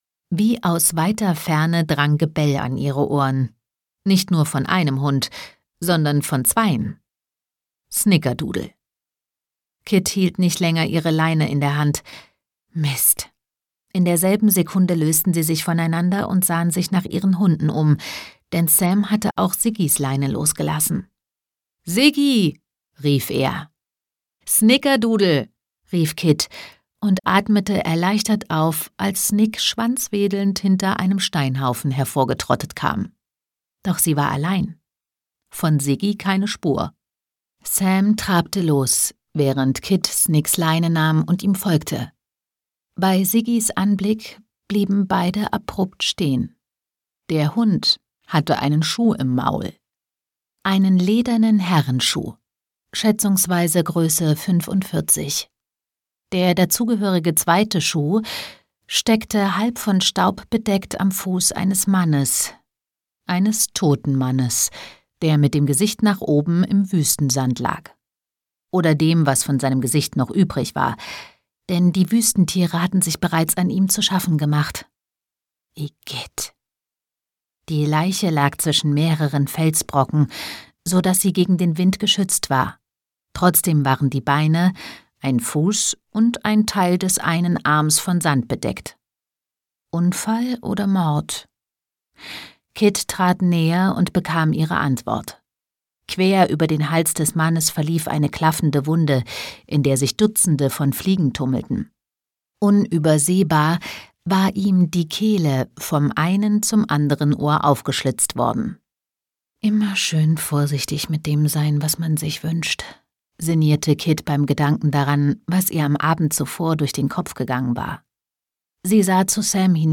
Ein spannendes Hörbuch, das uns atemlos zurücklässt!
Gekürzt Autorisierte, d.h. von Autor:innen und / oder Verlagen freigegebene, bearbeitete Fassung.